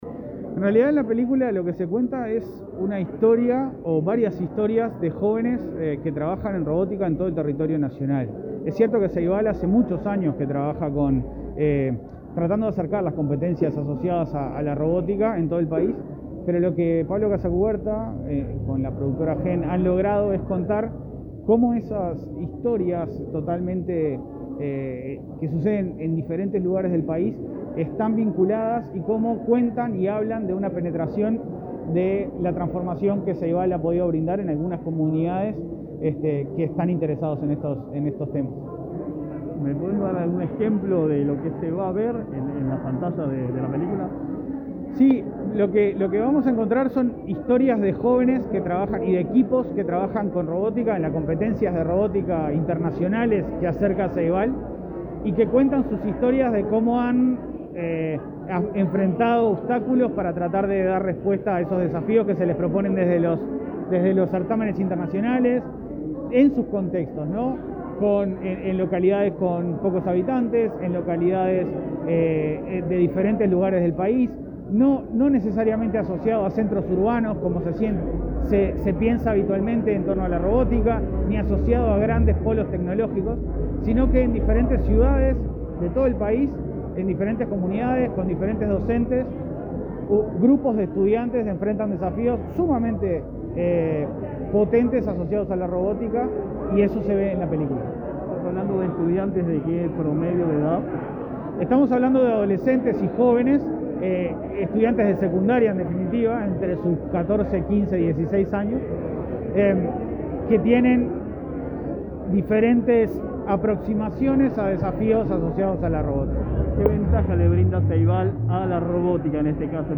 Entrevista al presidente del Plan Ceibal, Leandro Folgar